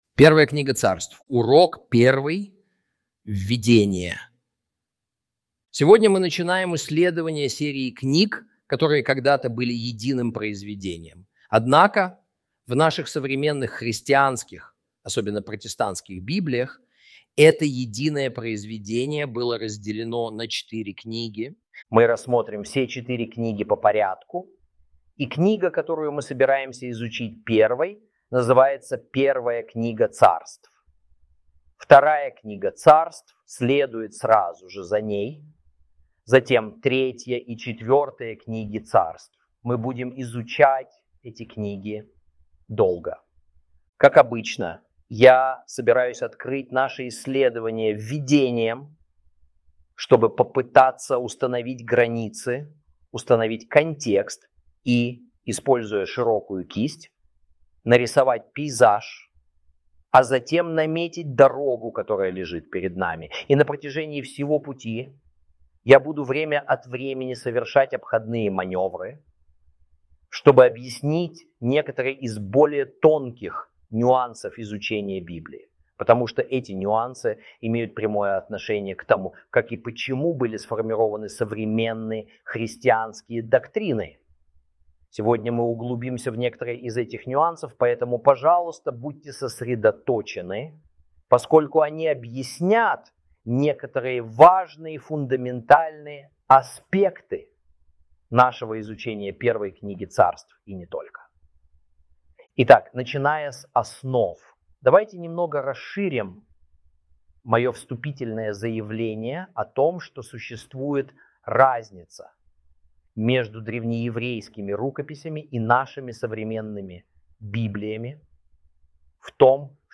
Video, audio and textual lessons